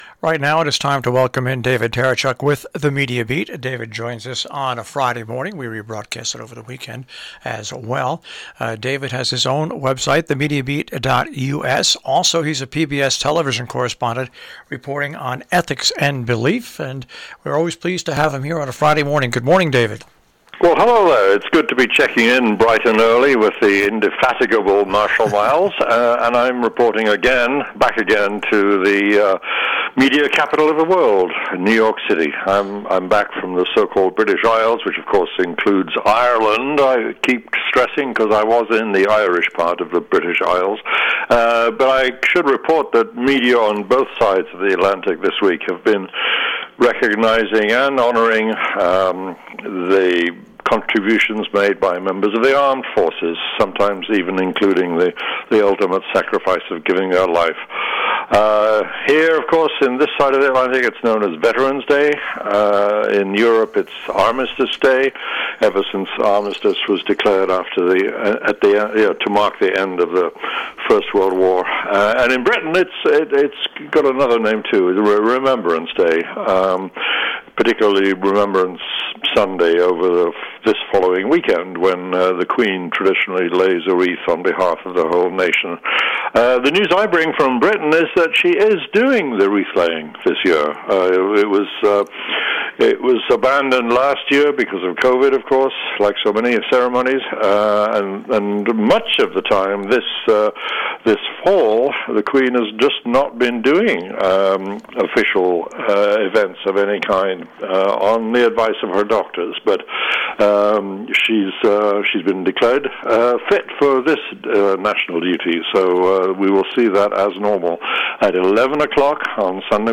A radio version of THE MEDIA BEAT appears every week on the NPR Connecticut station WHDD – live on Friday morning and rebroadcast over the weekend.